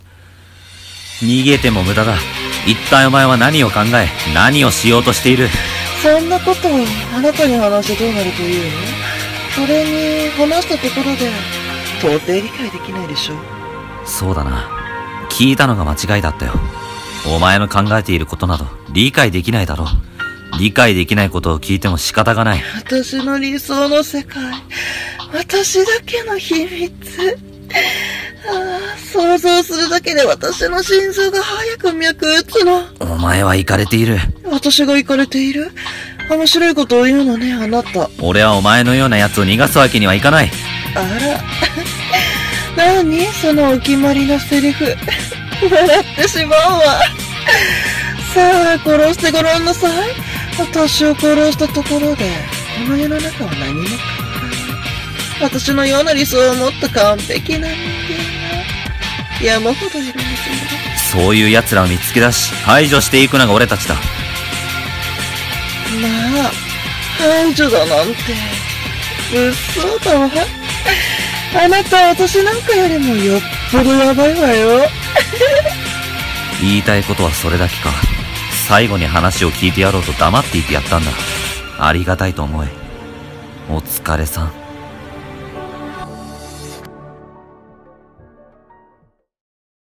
掛け合い声劇【正義と悪(怖い系)】